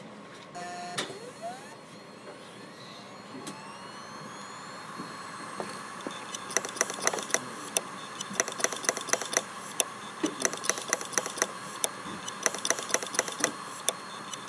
続いて、「シャーーーー」「カンカンカン」「ジーコジーコ」に代表される異音がする場合は、物理障害です。
「カンカンカン」のサンプル音
HDD-Errror-katakatakata.mp3